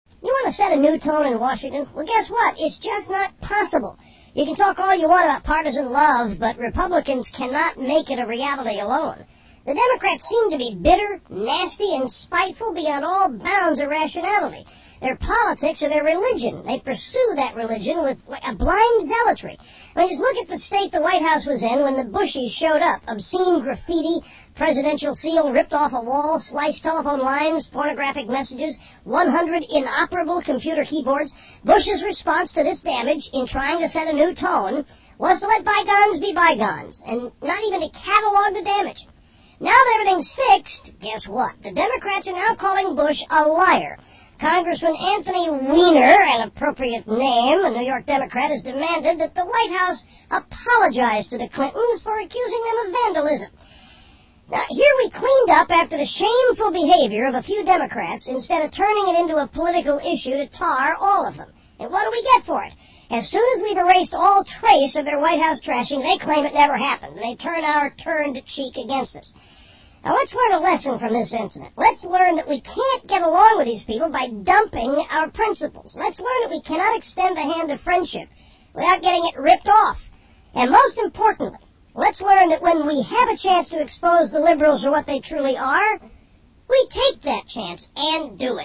Rush Limbaugh as a chipmunk!